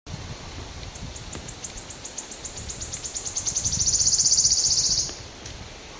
Bonsoir, Je vois une certaine ressemblance avec cet oiseau des sous bois de la foret de Rambouillet dont j'allais demander le nom ... mais bon, est la même espèce ou je me plante ?